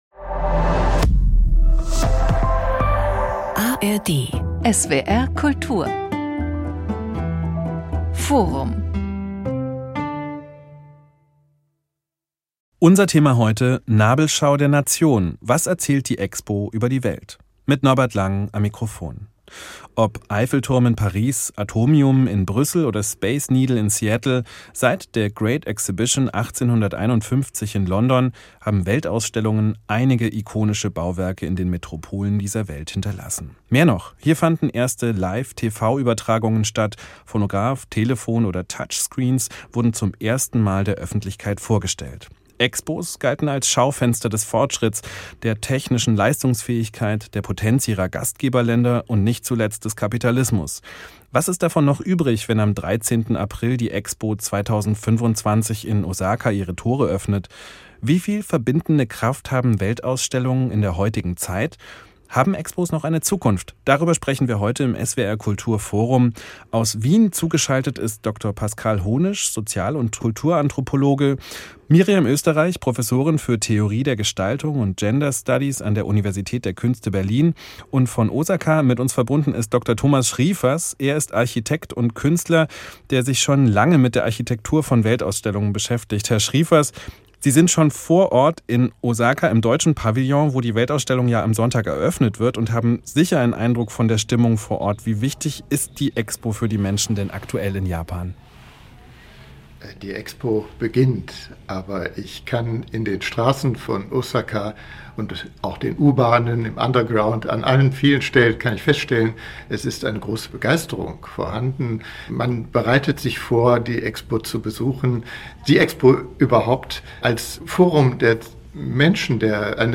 Im SWR Kultur Forum diskutieren wir über Gott und die Welt, über Fußball und den Erdball. Unsere Gäste kommen aus Wissenschaft, Literatur und Kultur – und manchmal auch aus der Politik.